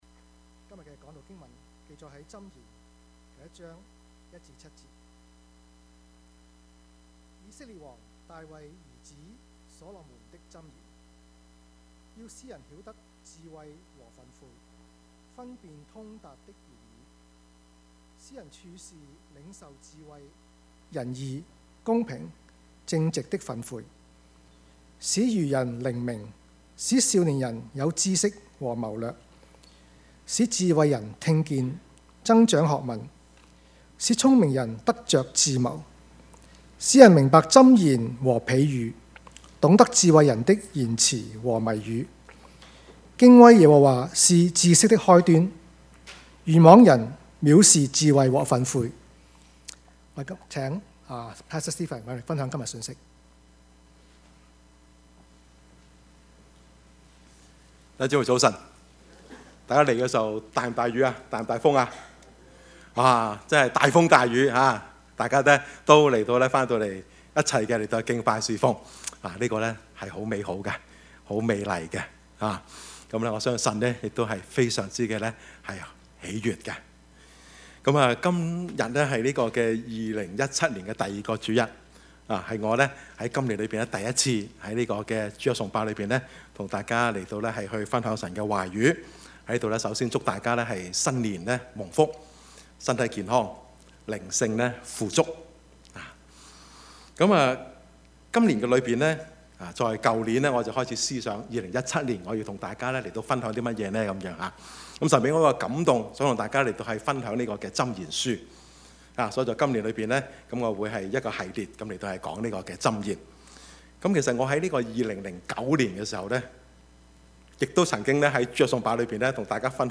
Service Type: 主日崇拜
Topics: 主日證道 « 起初 貧窮顯富足 »